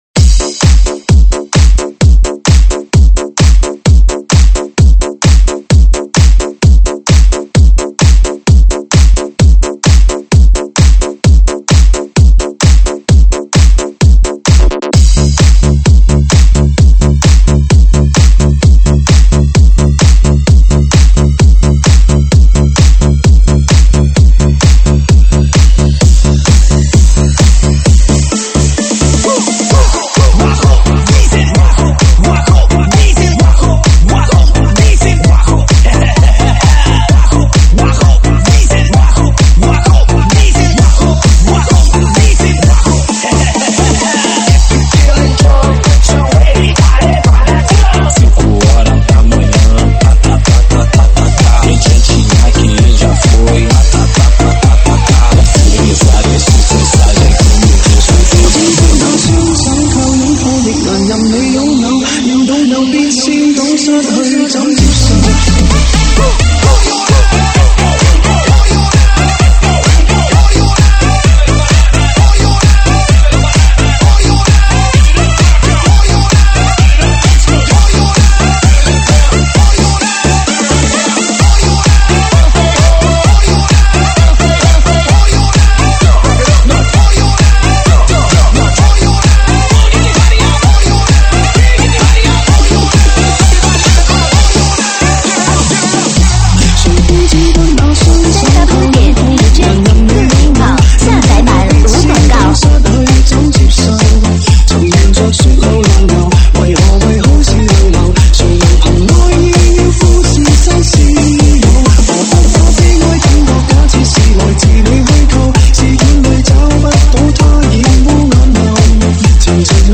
舞曲编号：77997
舞曲类别：中文舞曲